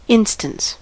instance: Wikimedia Commons US English Pronunciations
En-us-instance.WAV